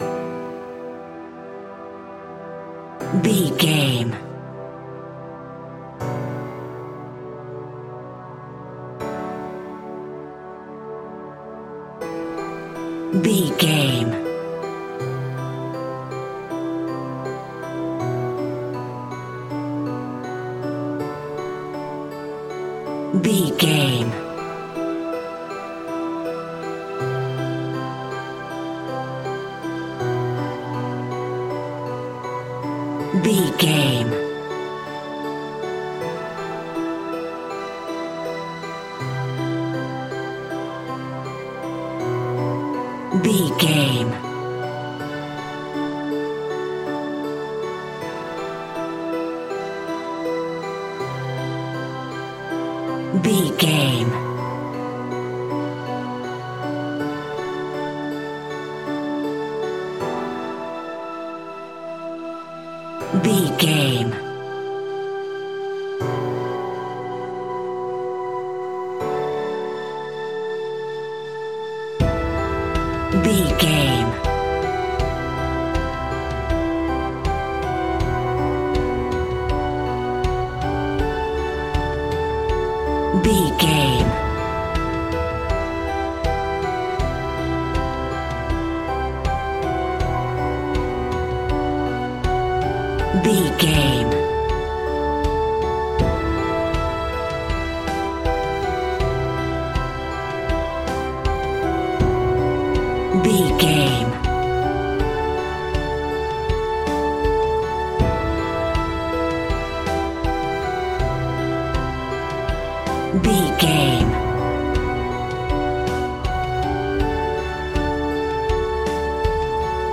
Powerful, epic and cinematic.
Epic / Action
In-crescendo
Aeolian/Minor
Slow
mournful
meditative
melancholy
dreamy
piano
synthesiser
strings
haunting